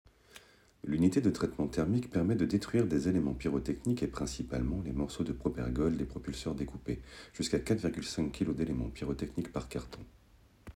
Voix off 2